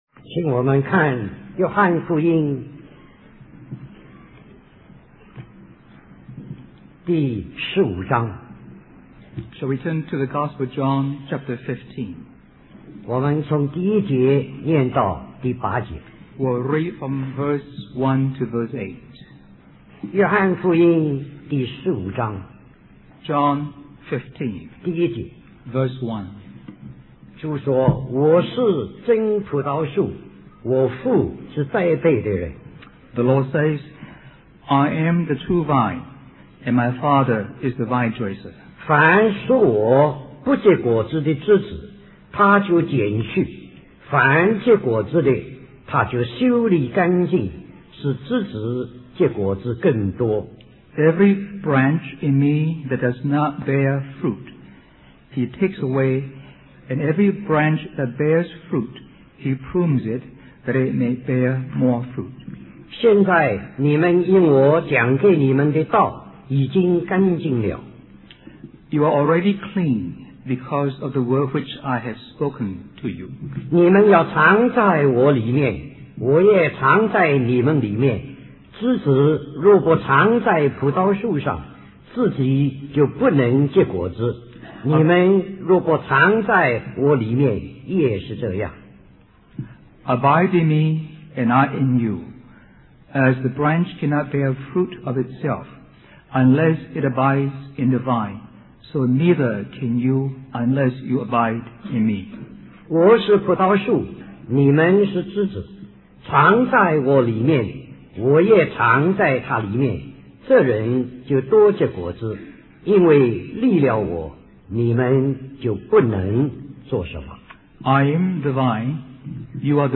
Special Conference For Service, Hong Kong